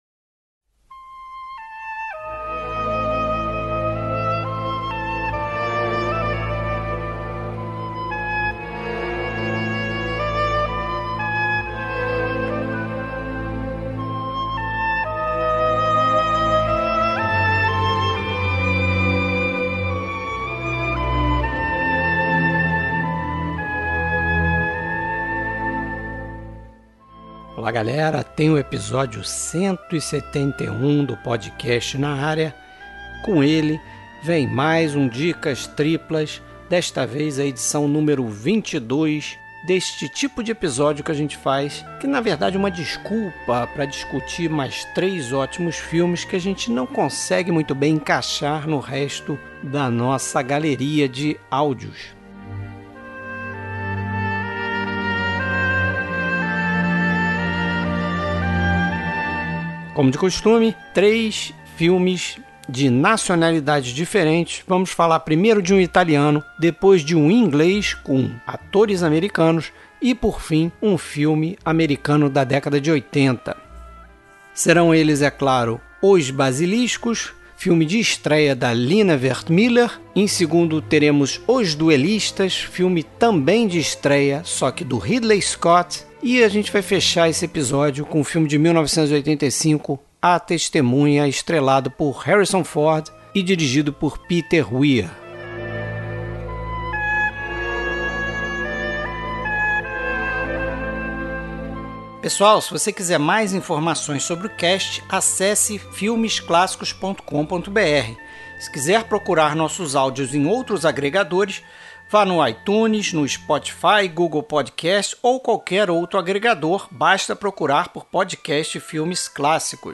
Trilha Sonora: Trilhas sonoras dos filmes comentados neste episódio.